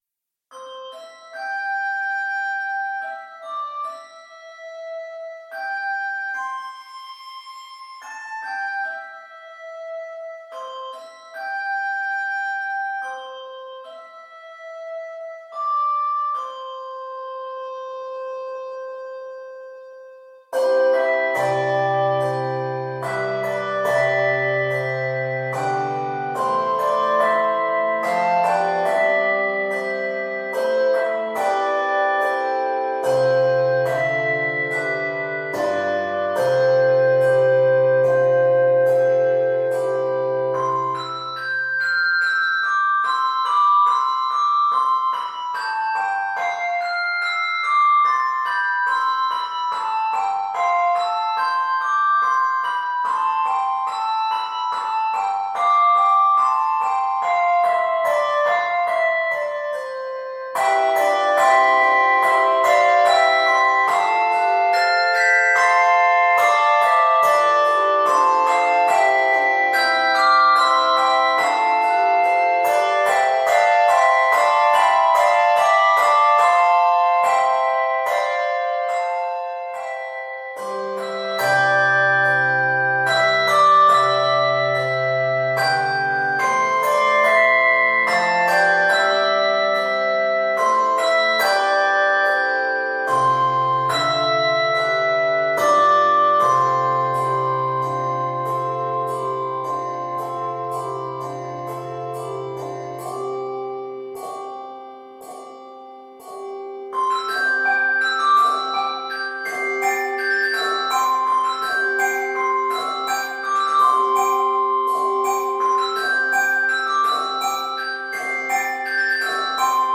Based on the Southern Harmony hymn tune